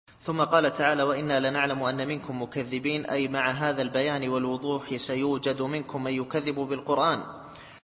التفسير الصوتي [الحاقة / 49]